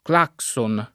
claxon [ kl # k S on ]